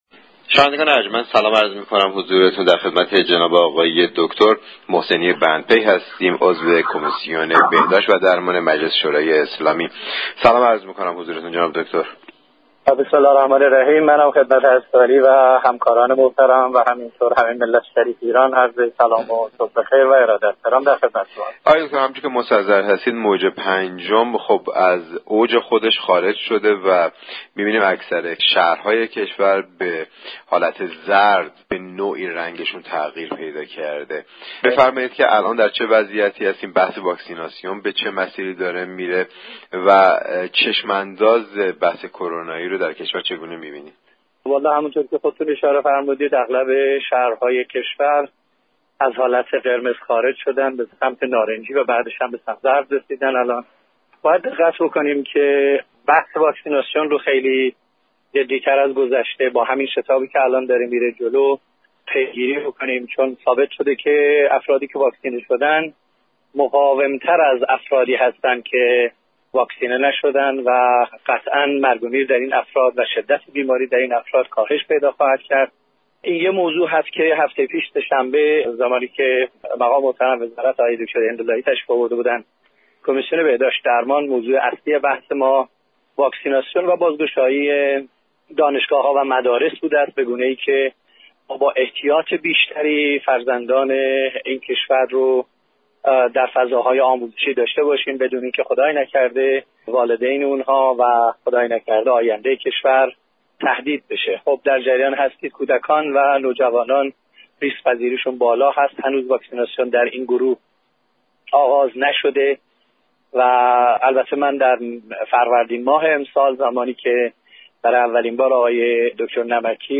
در گفت و گوی اختصاصی